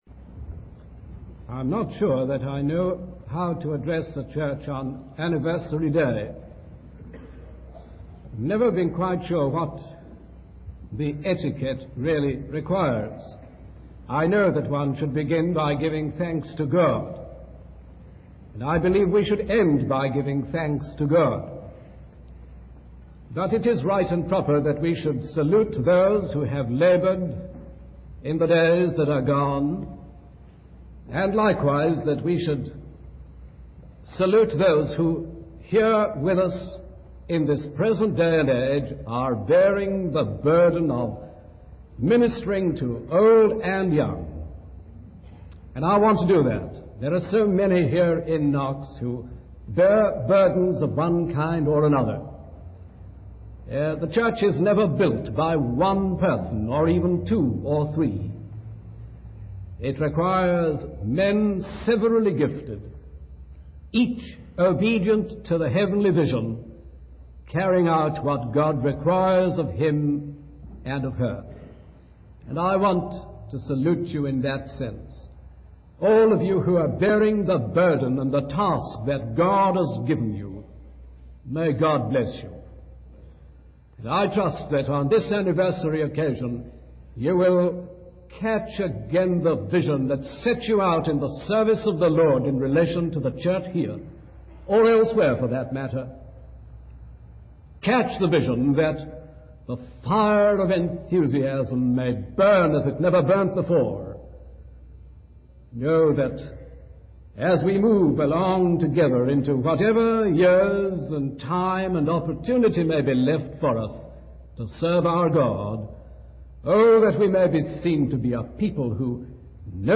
In this sermon, the preacher emphasizes the importance of the Gospel and its impact on the lives of believers. He focuses on the ministry of the Gospel in the Thessalonians' lives and how it transformed them. The preacher highlights the power of the Gospel message and the need for both the message and the messenger to have power.